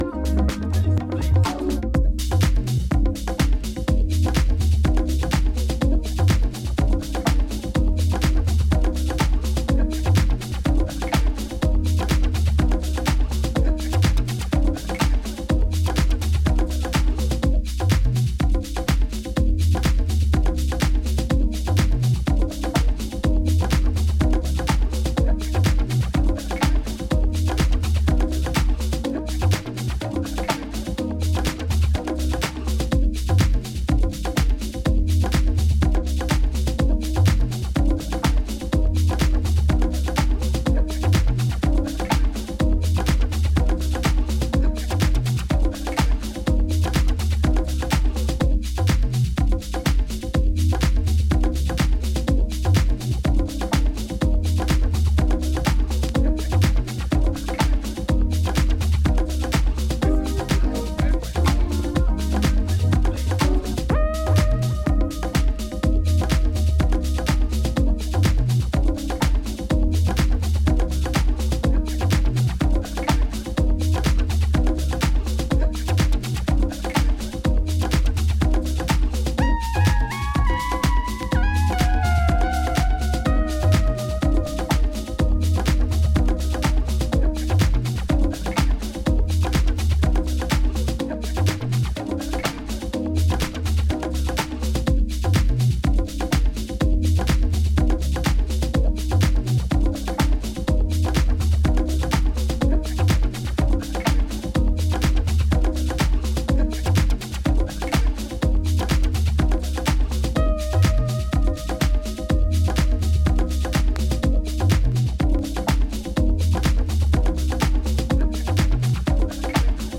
ボンゴを添えたオーガニックなグルーヴにスペーシー・シンセを添えた
いずれもジャジーでオーセンティックな魅力を秘めた